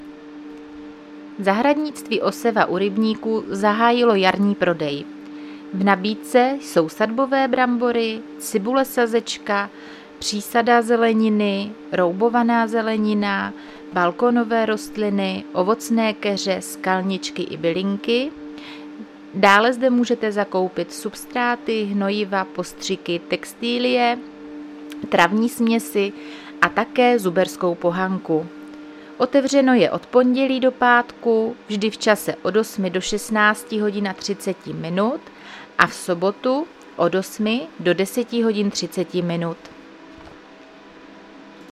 Záznam hlášení místního rozhlasu 9.4.2026
Zařazení: Rozhlas